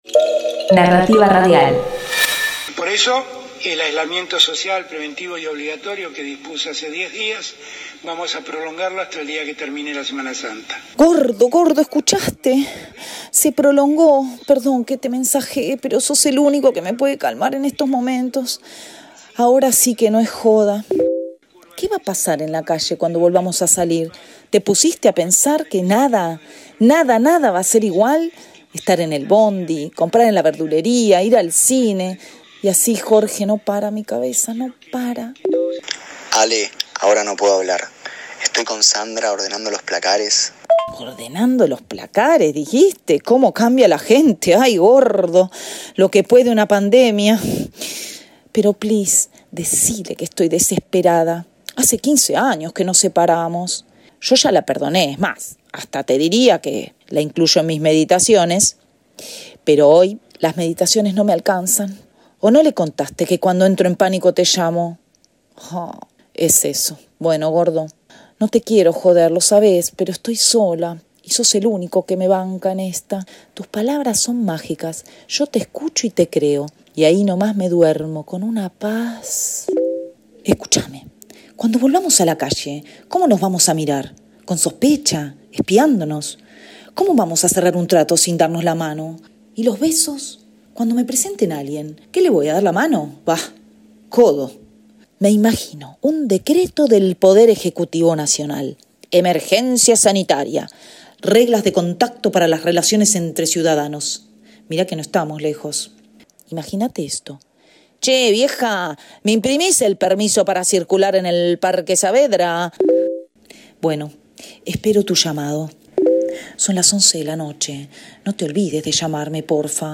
Proyecto urgente: Ficción por mensajes de voz
Ya que la imaginación es la locación imprescindible para poder llevarse a cabo, nos parece un buen desafío -y entrenamiento tanto en términos de escritura como de interpretación- tratar de contar mediante la limitación: el sólo recurso de la grabadora de voz en los teléfonos móviles.